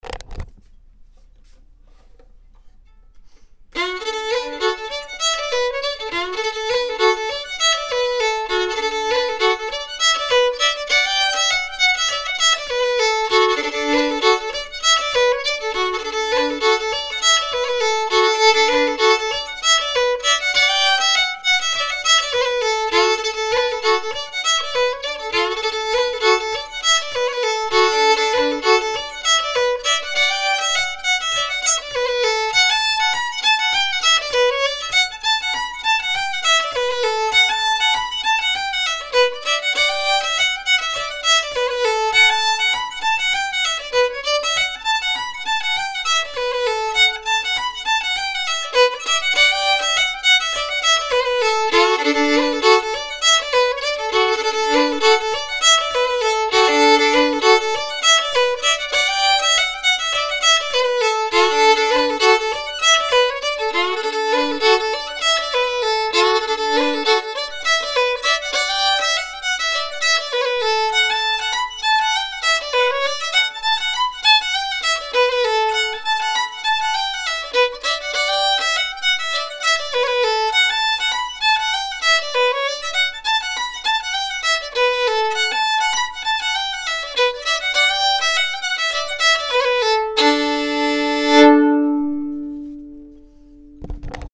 Reel | Silver Spear | 100 bpm – Sean-nós & Set Dance with Maldon